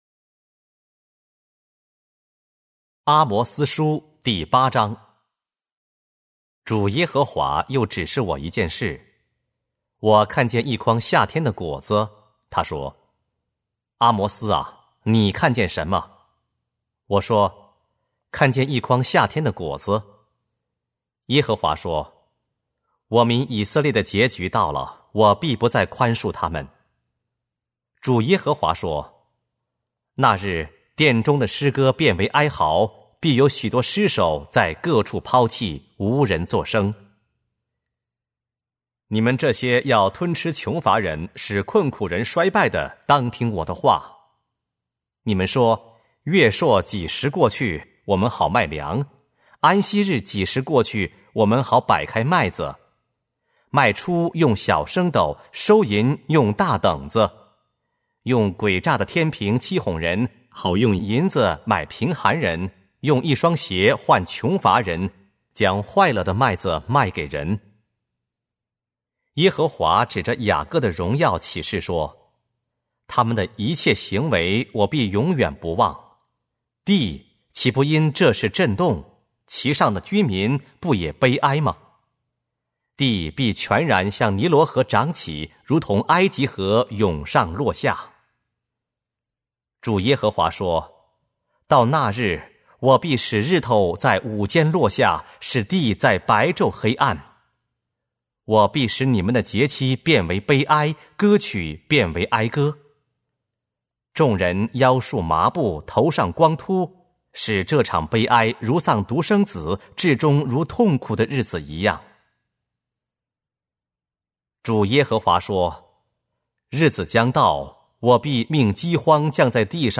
和合本朗读：阿摩司书